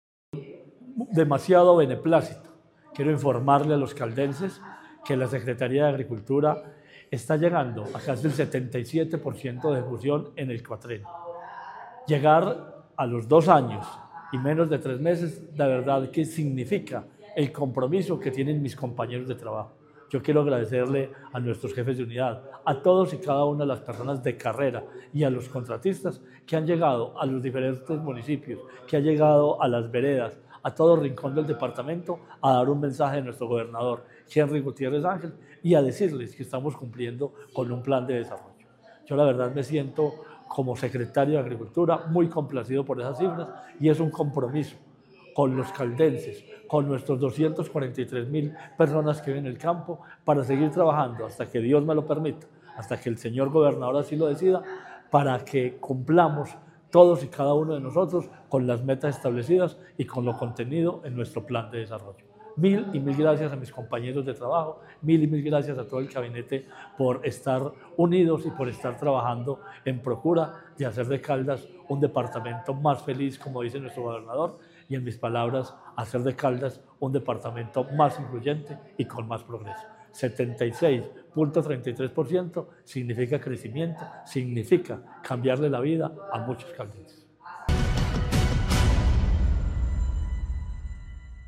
Marino Murillo Franco, secretario de Agricultura y Desarrollo Rural de Caldas.